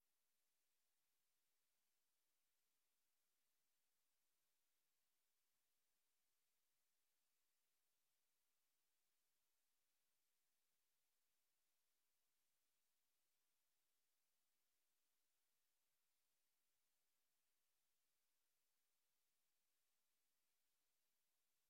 Beeldvormende vergadering(en) 07 januari 2025 20:00:00, Gemeente West Betuwe